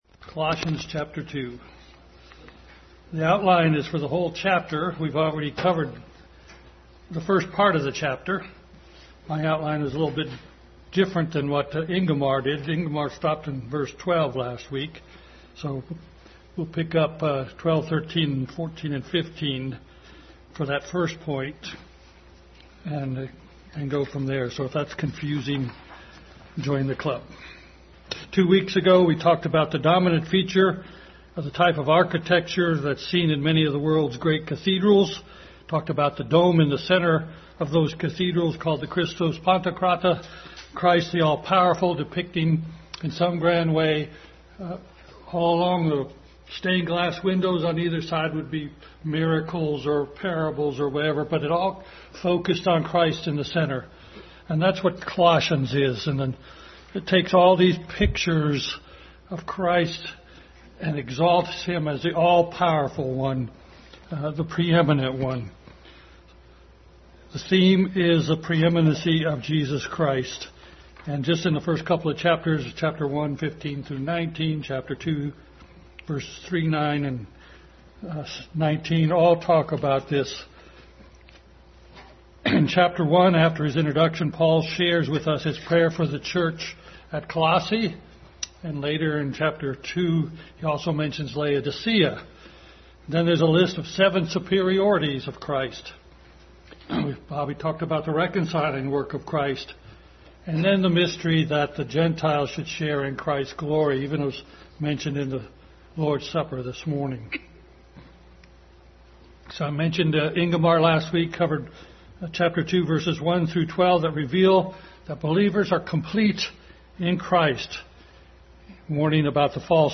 Adult Sunday School continued study in Colossians.